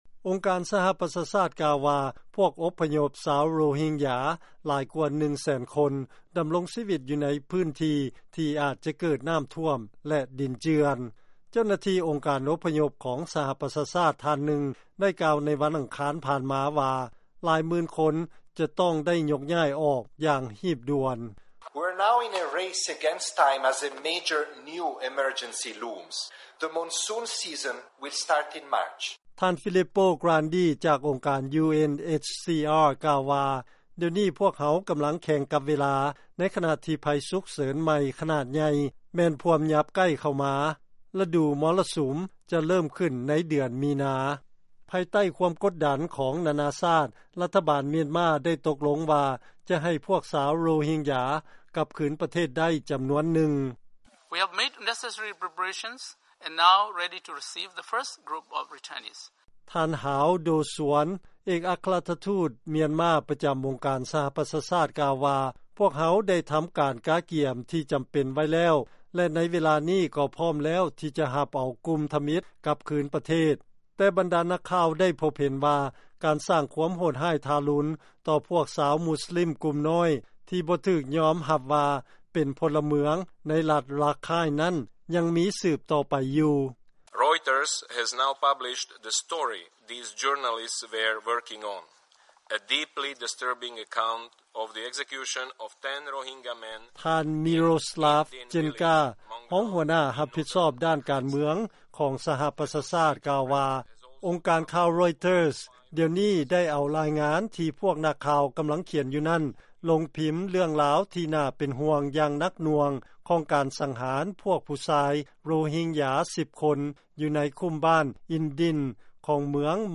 ເຊີນຟັງລາຍງານ ພວກອົບພະຍົບ ຊາວໂຣຮິງຢາ ຍັງສືບຕໍ່ ປະສົບກັບໄພຂົ່ມຂູ່ ແລະຍັງຈະປະເຊີນ ກັບໄພສຸກເສີນໃໝ່ ຕື່ມອີກ